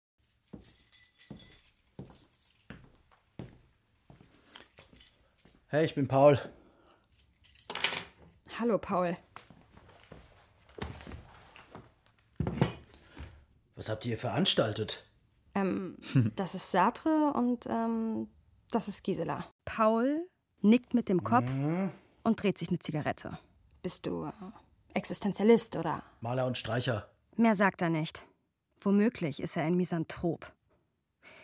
Meine Stimme klingt unverfälscht, jung und sympathisch und ist vielseitig einsetzbar für jugendliche bis männlich-zärtliche Charaktere.
Hörspiel. „Das Halbhalbe und das Ganzganze“
Rolle: Paul (NR)